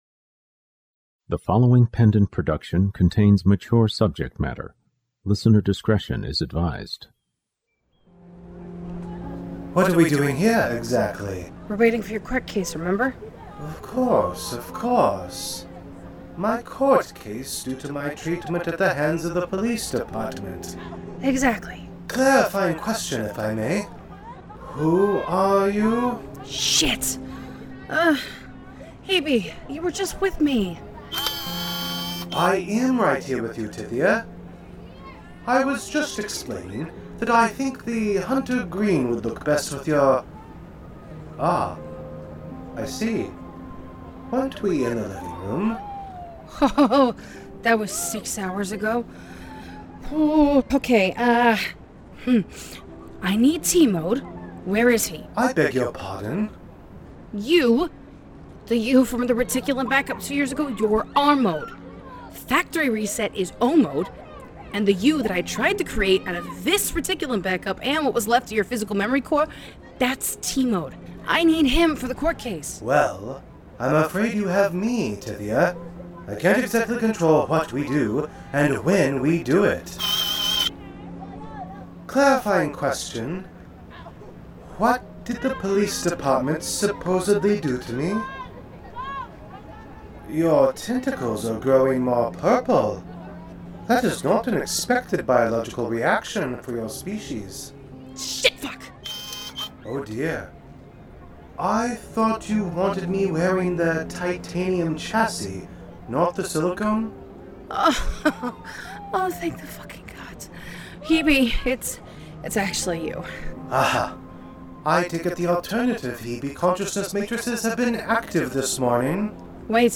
"The Kingery" is a full-cast, ongoing serial sci-fi crime drama.